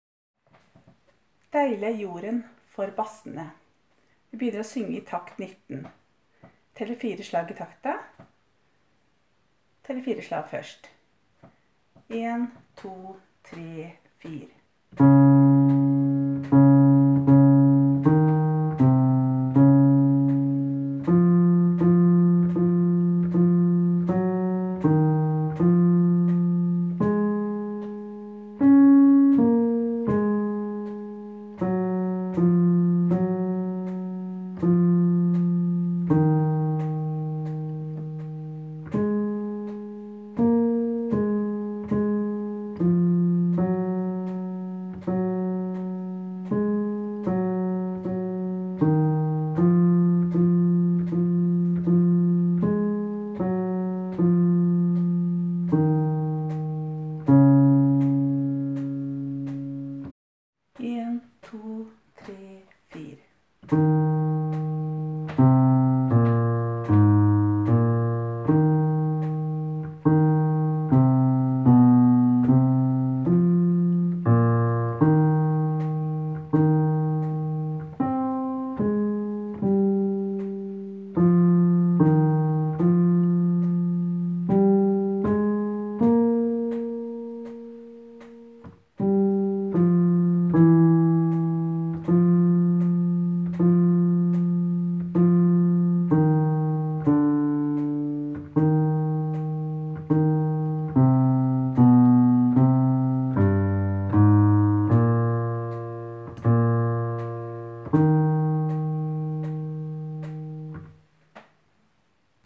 Jul 2017 Bass (begge konserter)
Deilig-er-jorden-Bassene.m4a